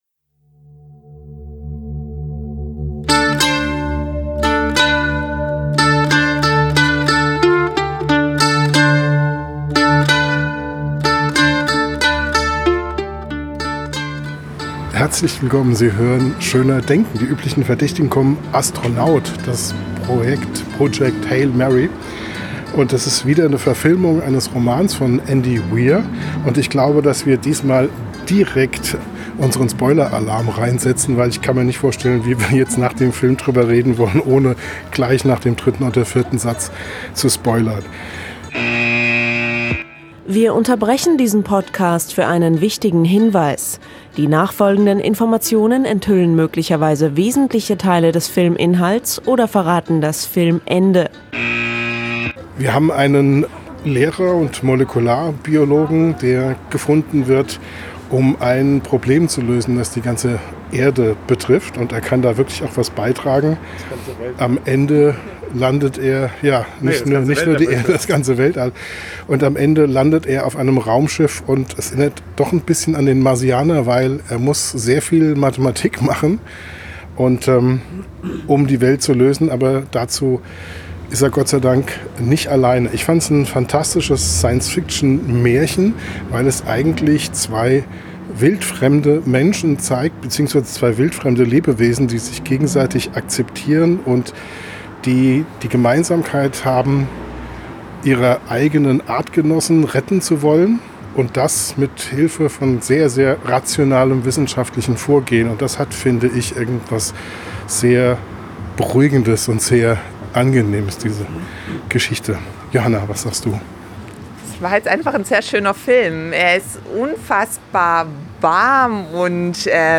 Im Podcast wird von fast Allen sehr viel gelobt, aber es wird auch klar: Wer sich nicht für Rocky erwärmen kann, findet nicht in den Film hinein. Am Mikrofon direkt nach dem Film